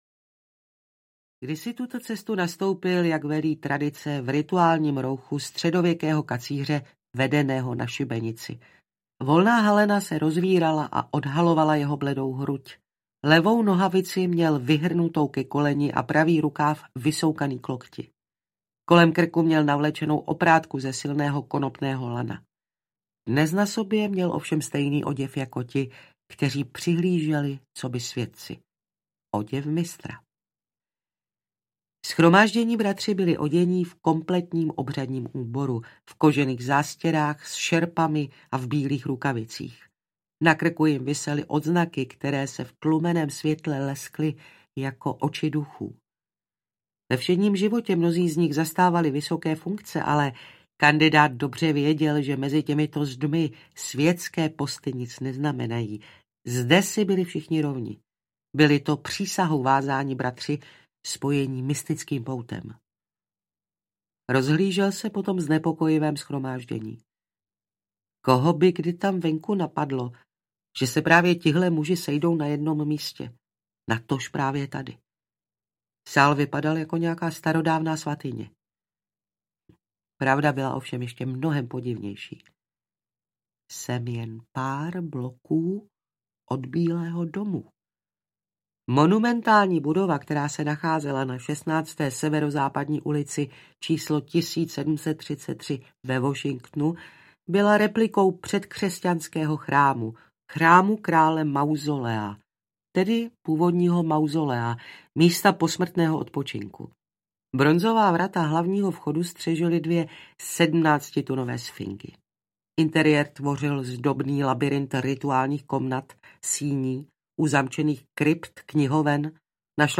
Ztracený symbol audiokniha
Ukázka z knihy
ztraceny-symbol-audiokniha